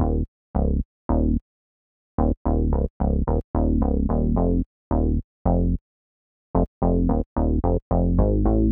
03 Bass PT2.wav